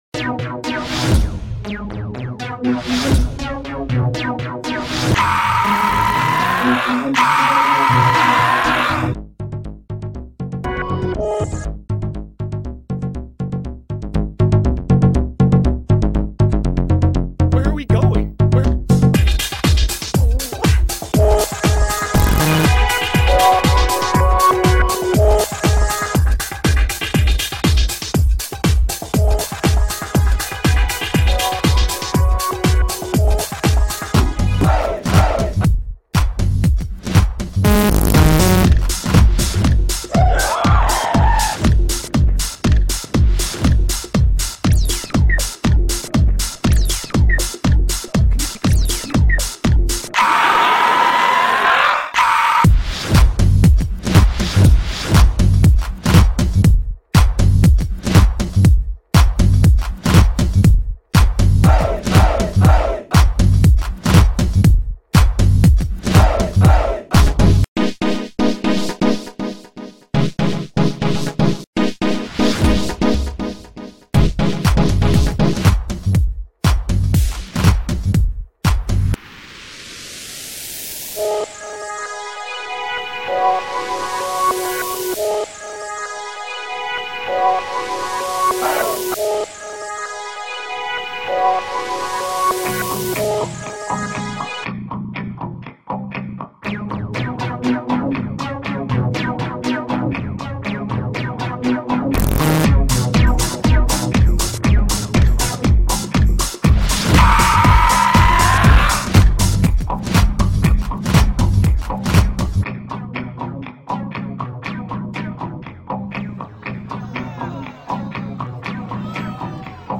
Virtual World Music